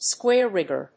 アクセントsquáre‐rígger